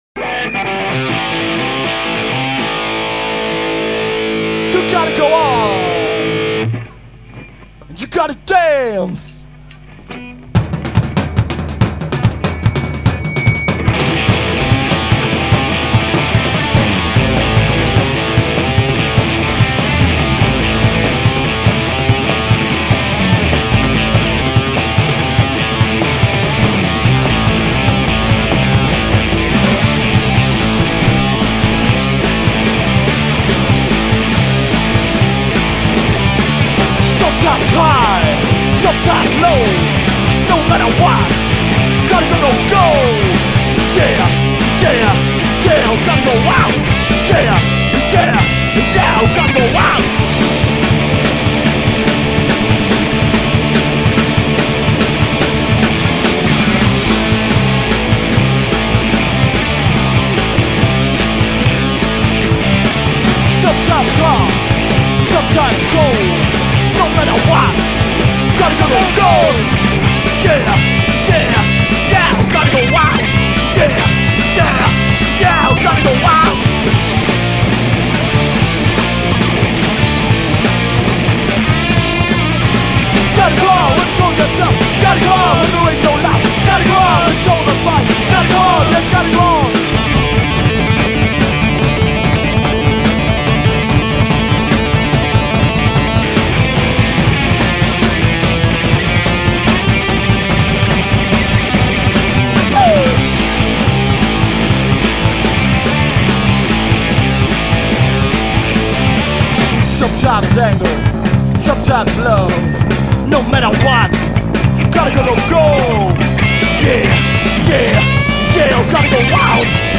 Live in der KVU: Mitschnitte vom Konzert am 11.12.2004